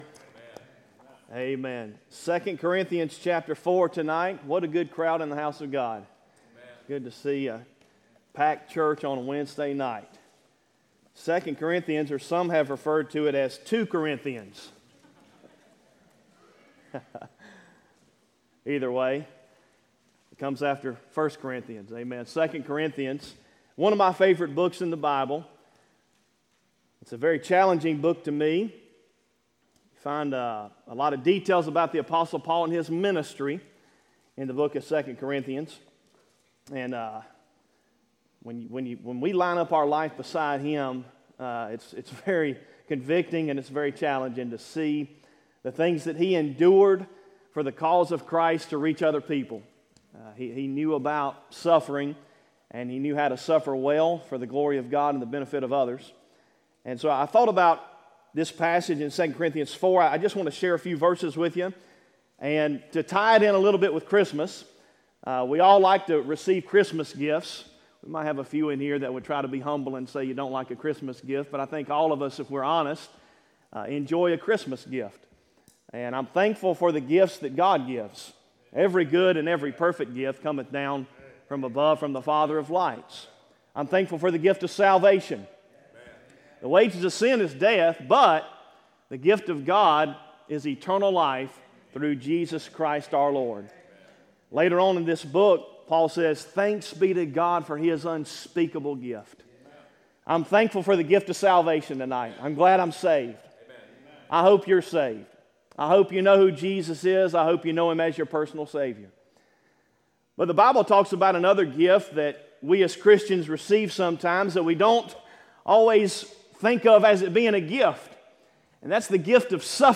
Sermons Archive • Fellowship Baptist Church - Madison, Virginia